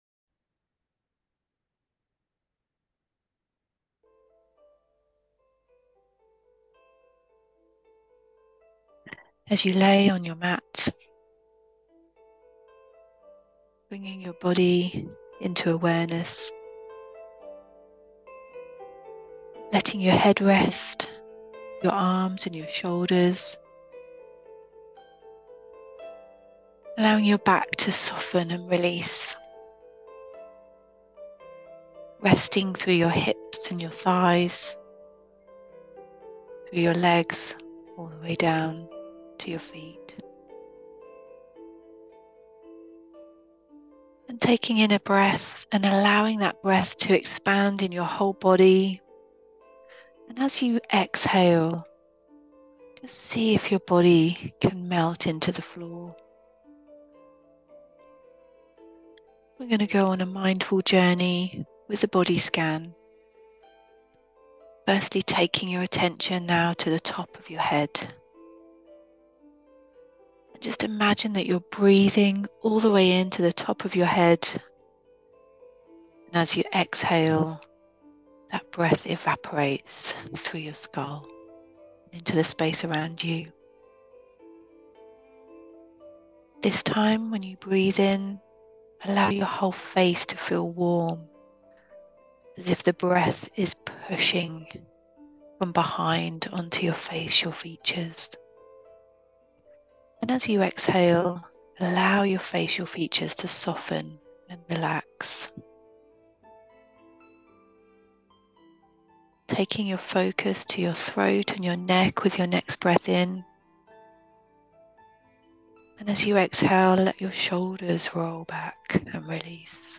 Try this recorded meditation
Find a comfortable place to rest and tune-in to this guided, mindful meditation.
5 minute Body Scan.m4a